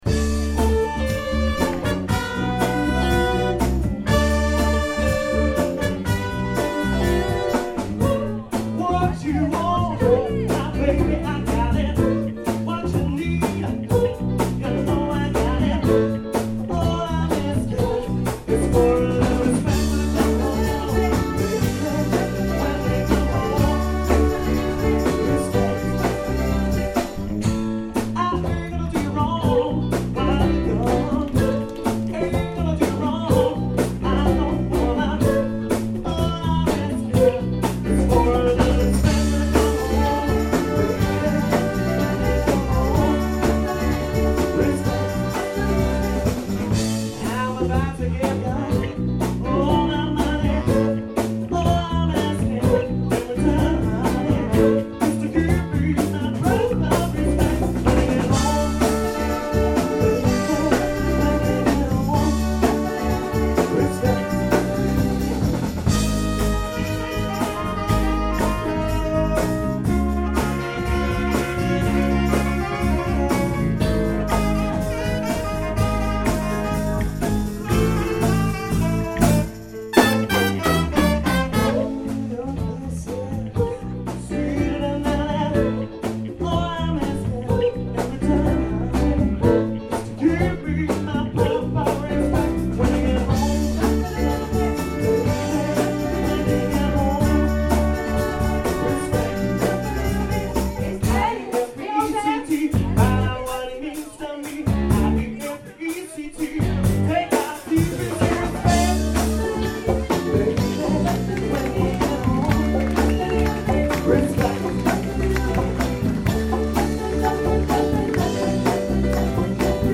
Big  Band - Rythm'n Blues